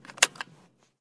* Change buckle.ogg and unbuckle.ogg from stereo to mono
unbuckle.ogg